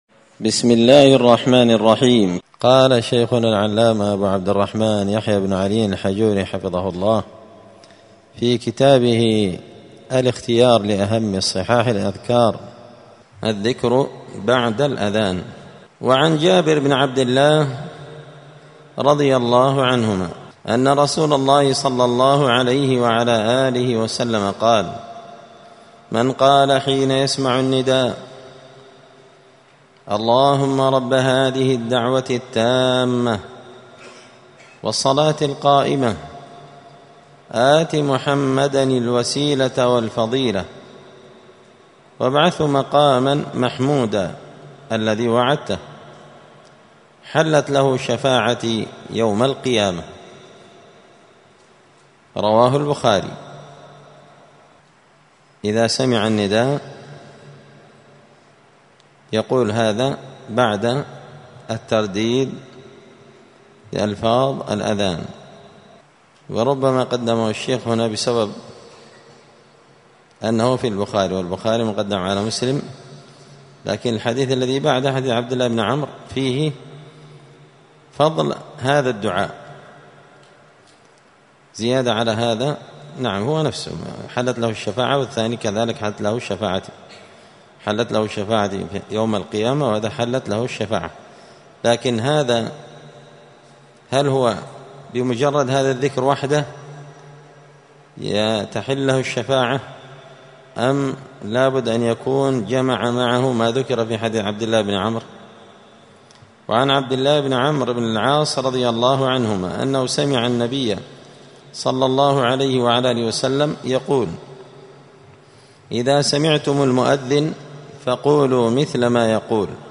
*{الدرس الخامس (5) الذكر بعد الأذان}*